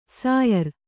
♪ sāyar